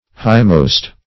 highmost - definition of highmost - synonyms, pronunciation, spelling from Free Dictionary Search Result for " highmost" : The Collaborative International Dictionary of English v.0.48: Highmost \High"most`\, a. Highest.
highmost.mp3